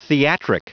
Prononciation du mot theatric en anglais (fichier audio)
Prononciation du mot : theatric
theatric.wav